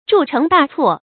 注音：ㄓㄨˋ ㄔㄥˊ ㄉㄚˋ ㄘㄨㄛˋ
鑄成大錯的讀法